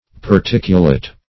Particulate \Par*tic"u*late\ (p[aum]r*t[i^]k"[-u]*l[asl]t), a.